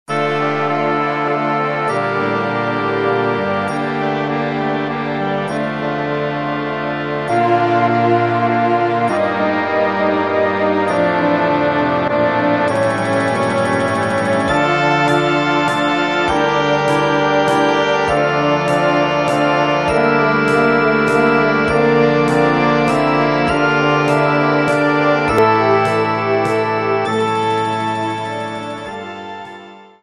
Kolędy